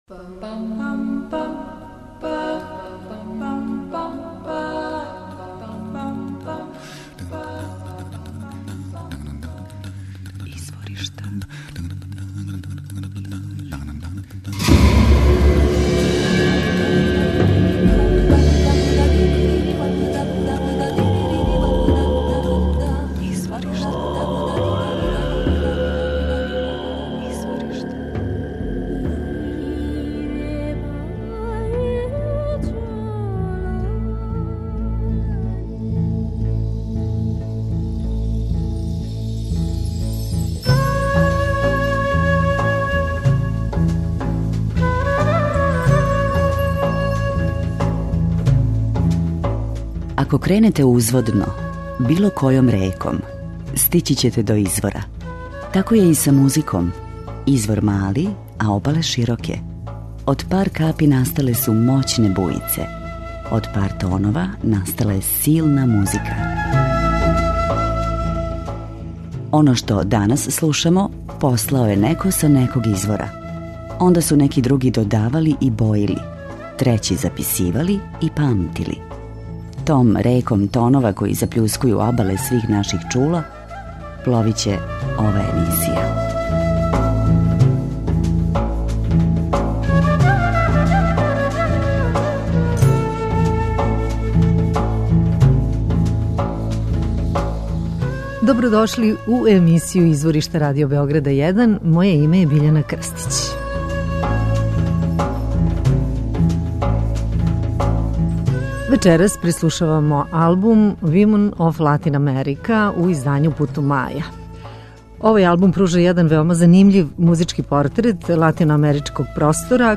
Слушаћемо певачице